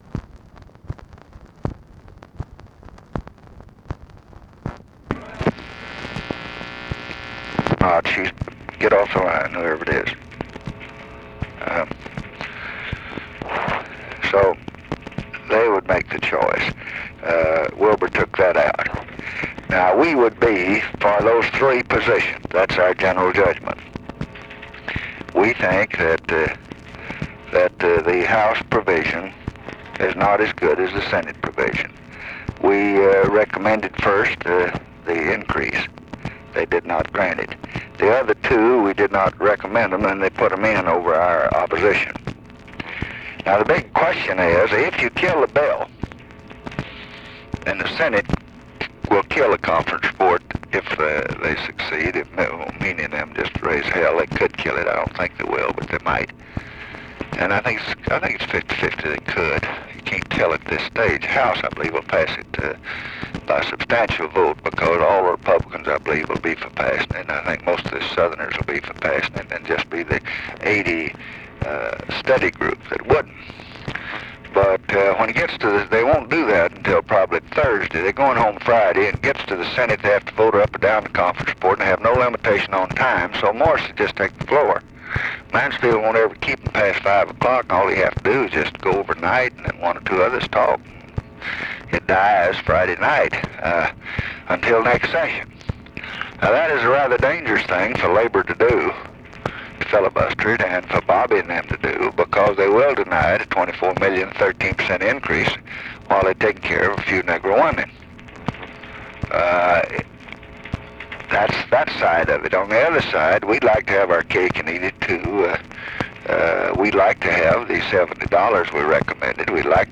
Conversation with GEORGE CHRISTIAN, December 11, 1967
Secret White House Tapes